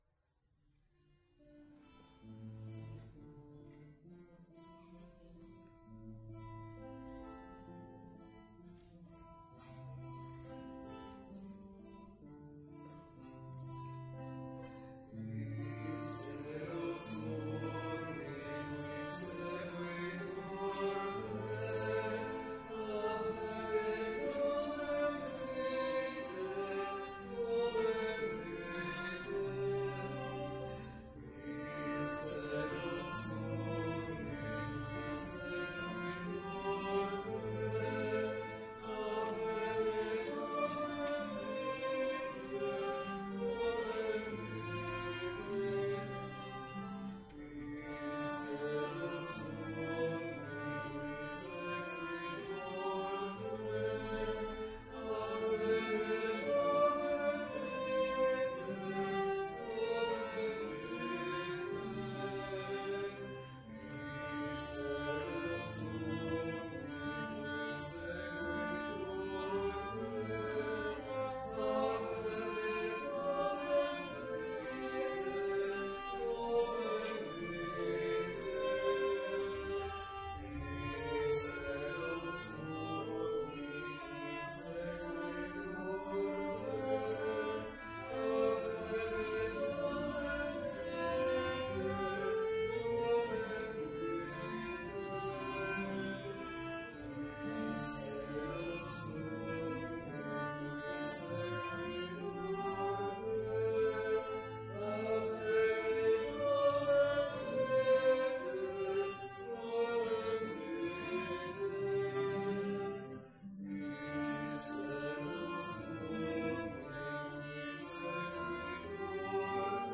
Pregària de Taizé a Mataró... des de febrer de 2001
Parròquia M.D. de l'Esperança - Diumenge 25 de setembre de 2022
Vàrem cantar...